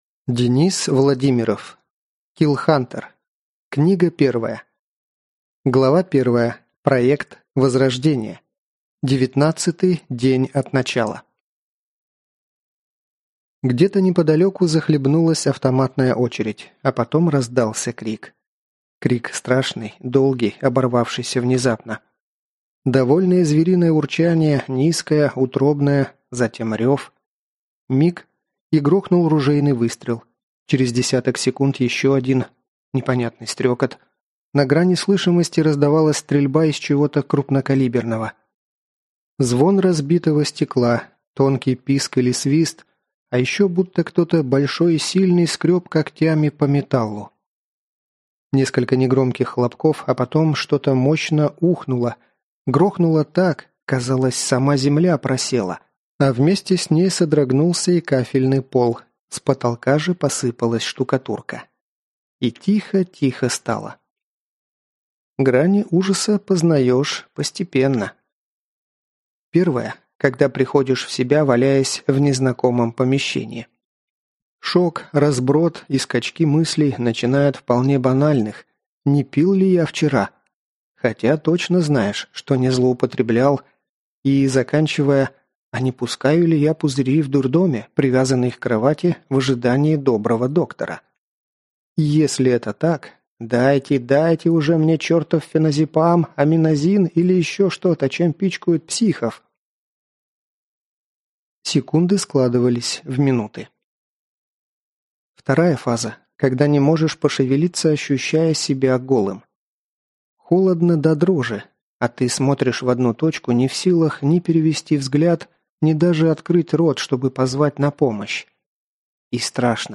Прослушать фрагмент аудиокниги Киллхантер Денис Владимиров Произведений: 4 Скачать бесплатно книгу Скачать в MP3 Вы скачиваете фрагмент книги, предоставленный издательством